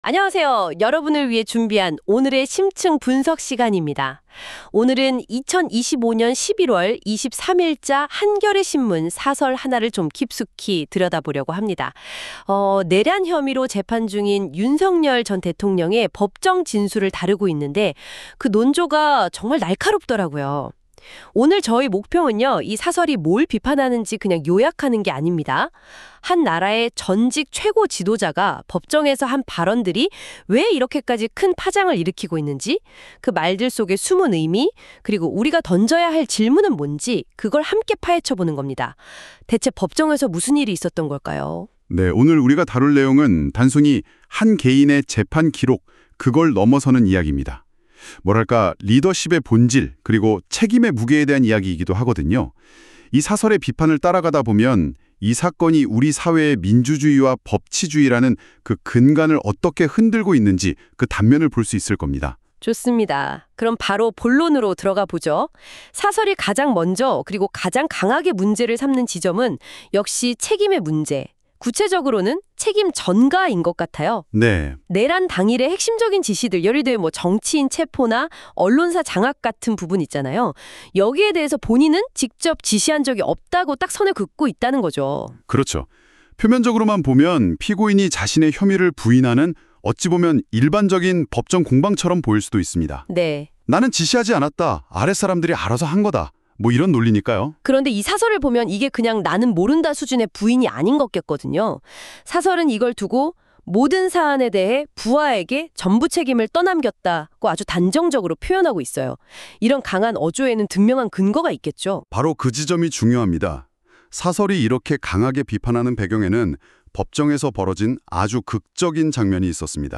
오늘도 AI와 함께 작업한 사설에 대한 제 논평입니다. 이번에는 윤석열 재판에 대한 사설인데요, 윤석열은 자기 부하들에게 책임을 미루었습니다.